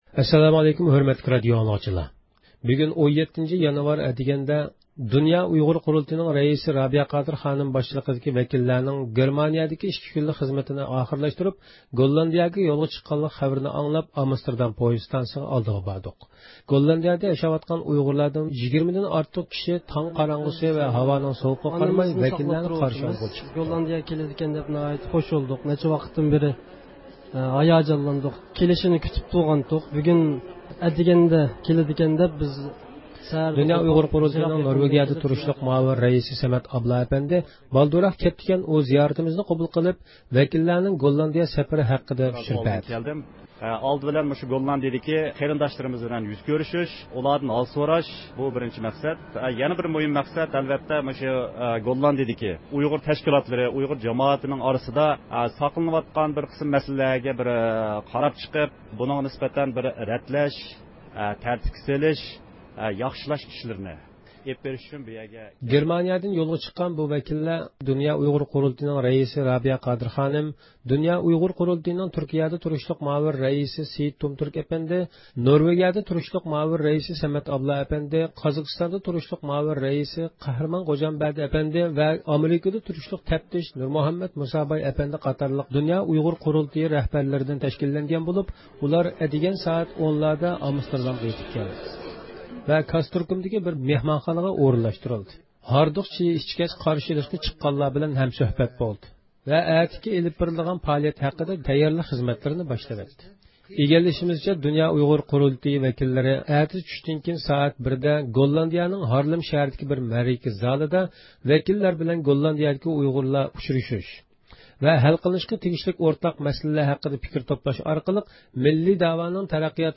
ئىختىيارىي مۇخبىرىمىز